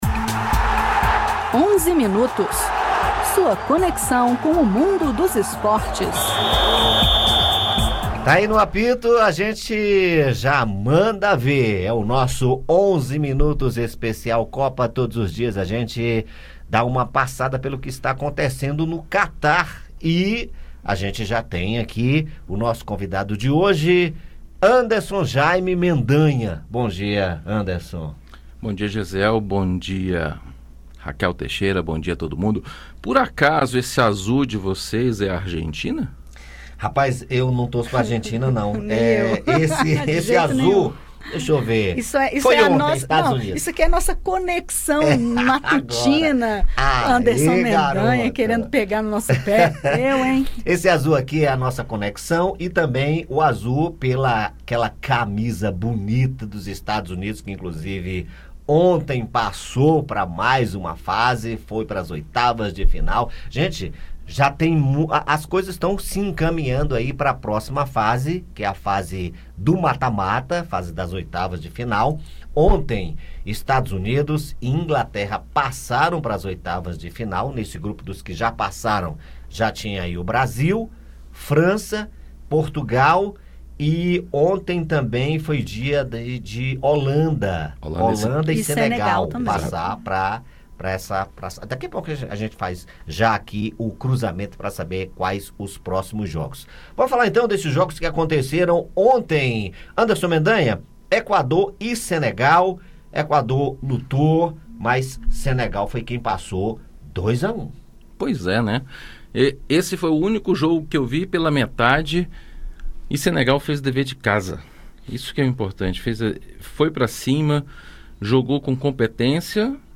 Comentários sobre a classificação e os jogos desta quarta-feira (30)